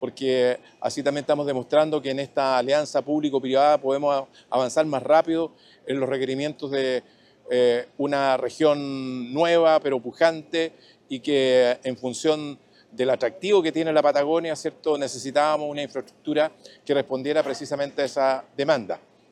Jorge Díaz Guzmán / Delegado Presidencial Regional